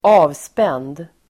Ladda ner uttalet
avspänd adjektiv, relaxed Uttal: [²'a:vspen:d]